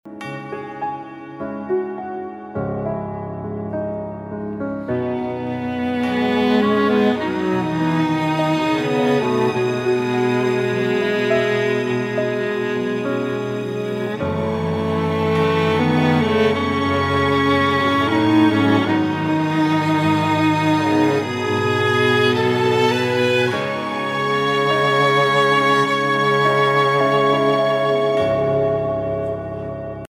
آهنگ موبایل بی کلام (ملایم و نسبتا محزون)